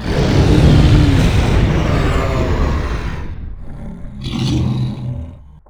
combat / creatures / dragon / he / die1.wav